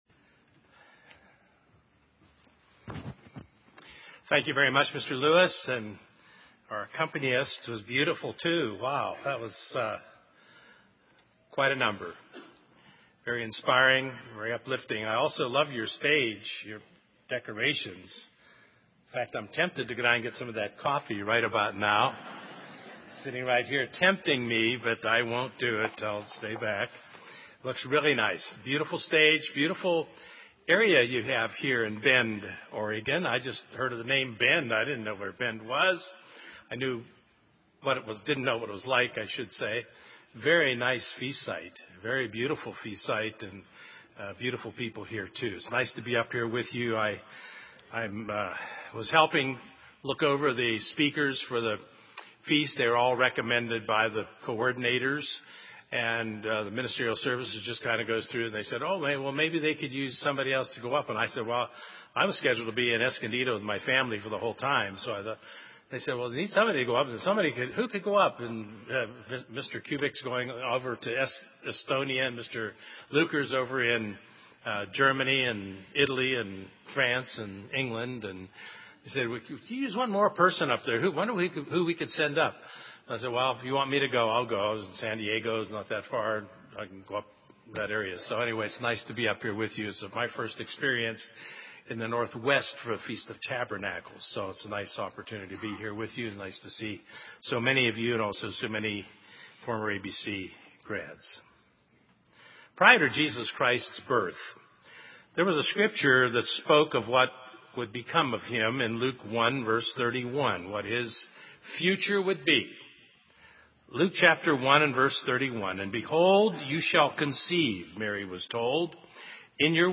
This sermon was given at the Bend, Oregon 2011 Feast site.